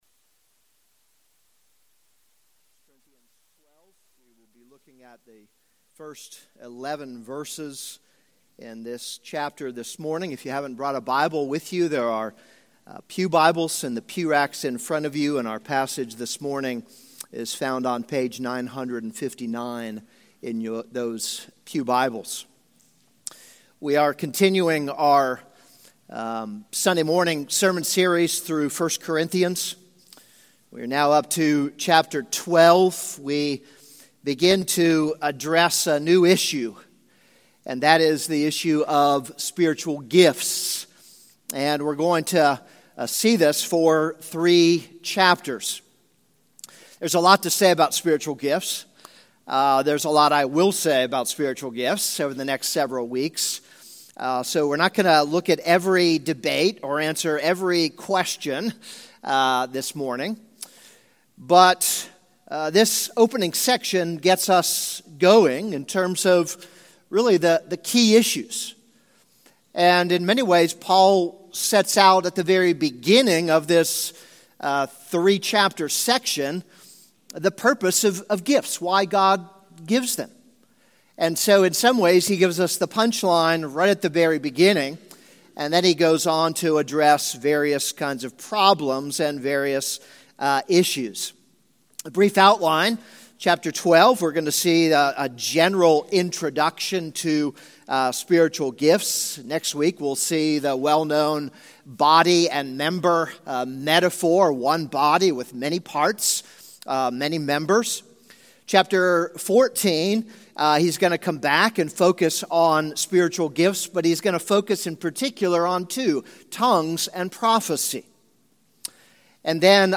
This is a sermon on 1 Corinthians 12:1-11.